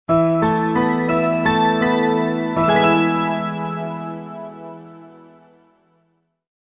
Звуки логотипа